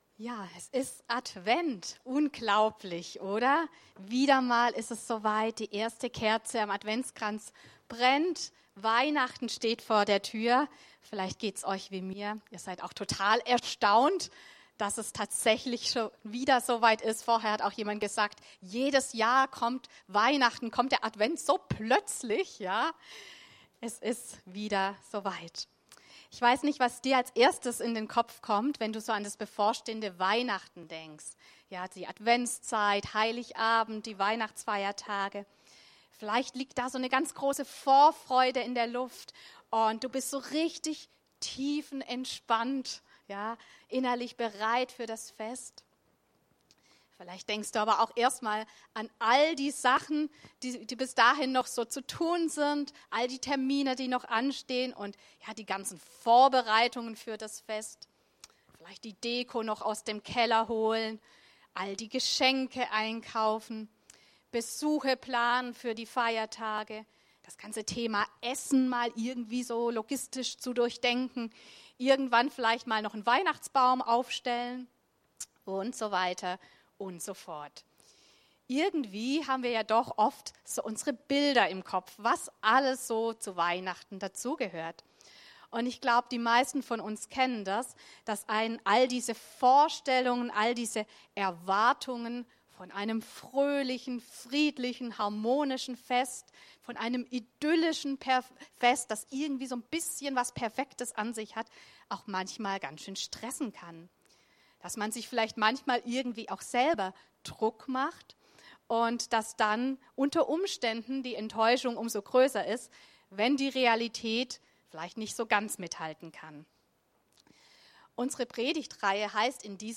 Eine Predigt aus der Reihe '(un)perfekte Weihnachten'